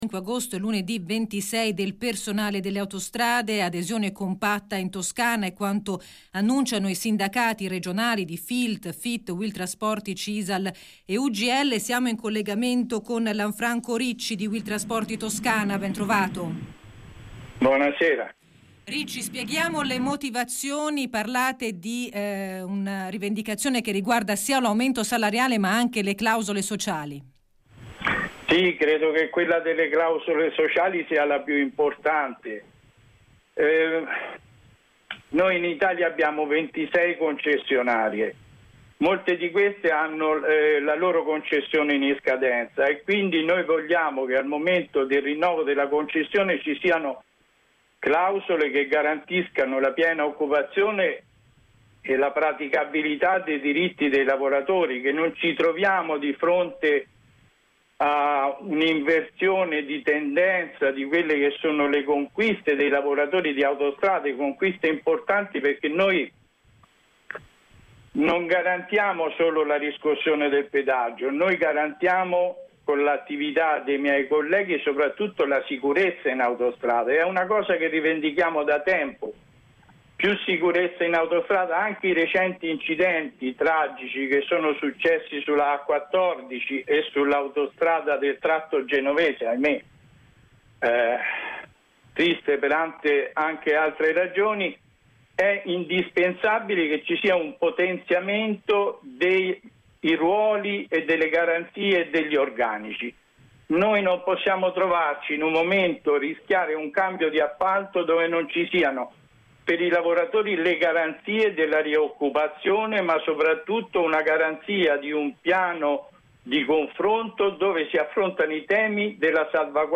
ASPI DT4 : sciopero 25 e 26 agosto 2019 - intervista